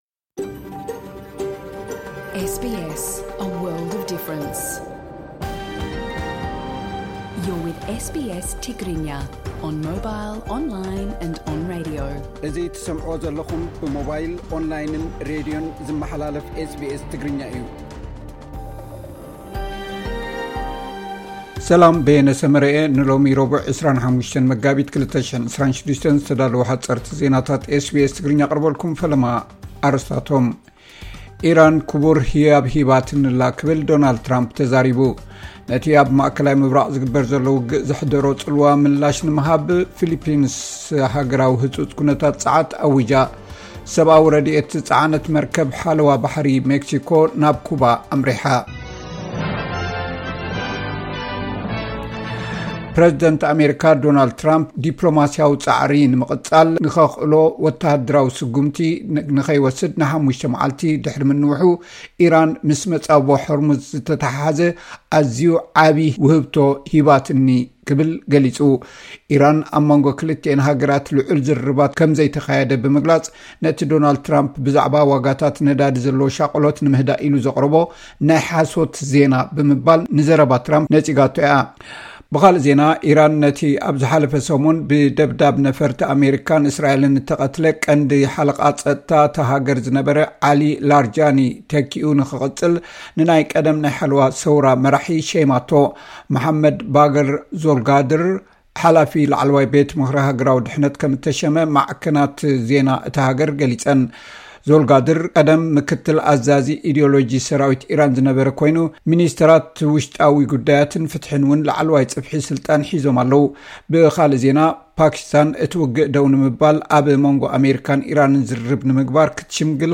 "ኢራን ክቡር ህያብ ሂባትኒ ኣላ።" ዶናልድ ትራምፕ፡ ሓጸርርቲ ዜናታት SBS ትግርኛ (25 መጋቢት 2026)